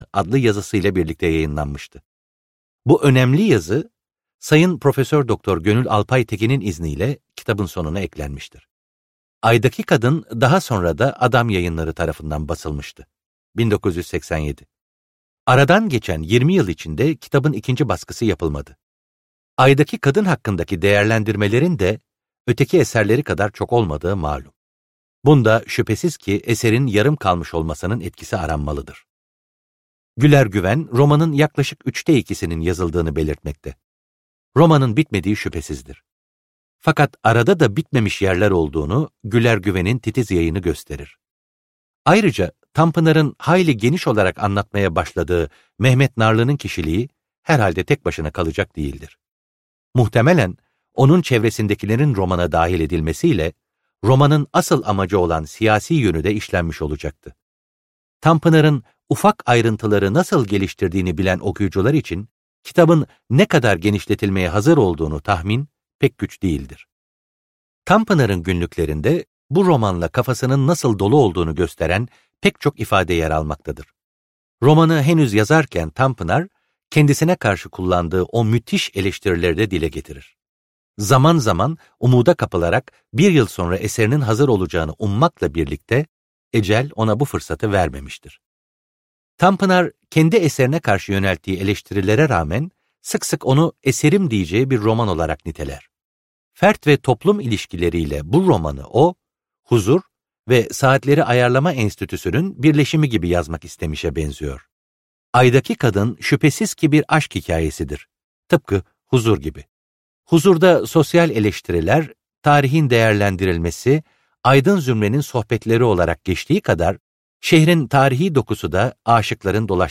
Aydaki Kadın - Seslenen Kitap